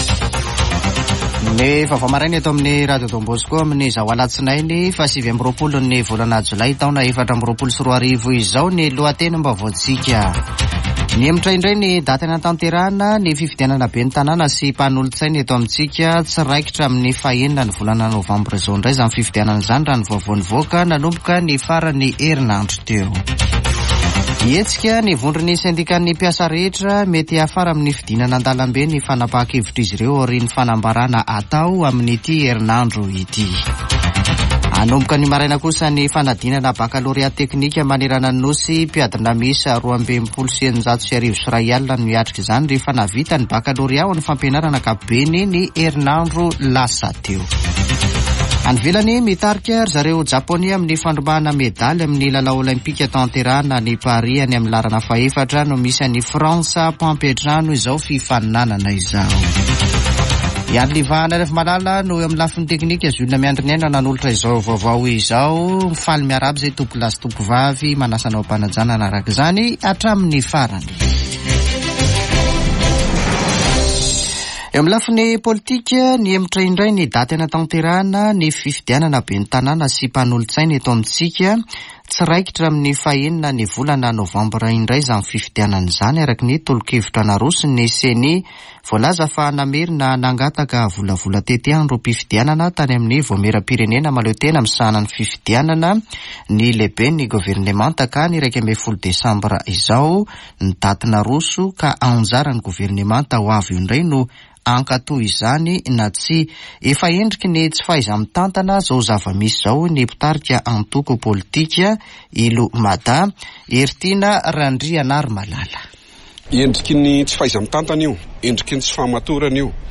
[Vaovao maraina] Alatsinainy 29 jolay 2024